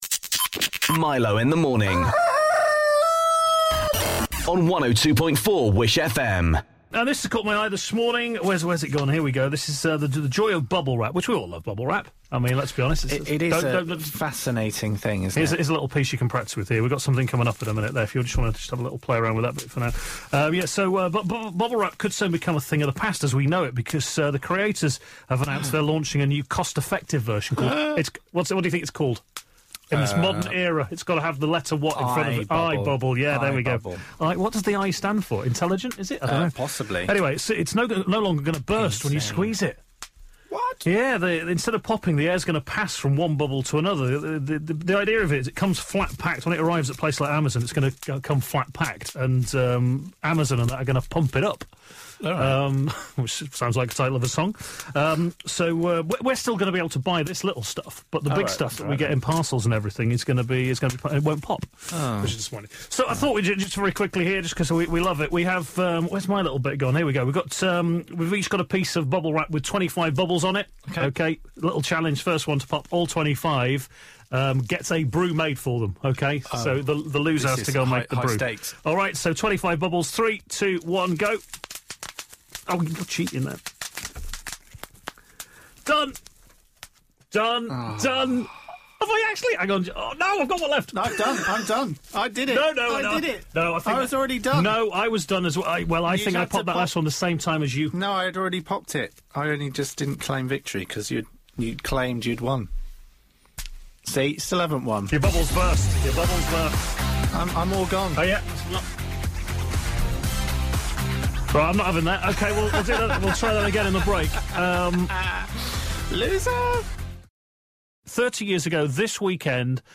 Listen back to our chat